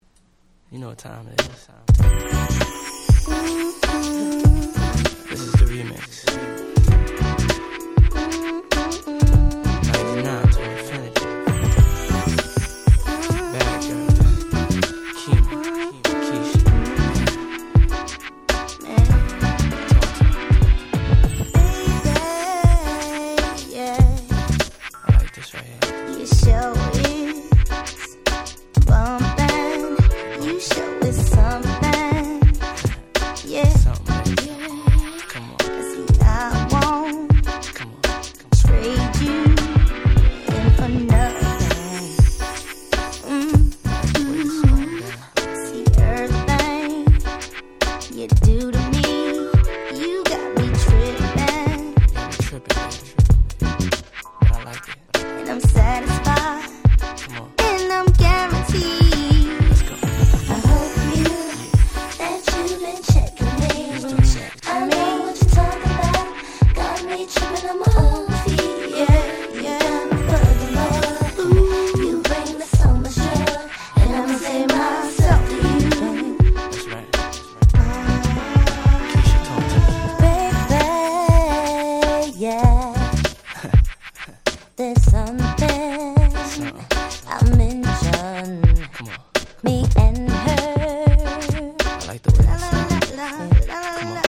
99' Smash Hit R&B !!